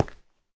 stone4.ogg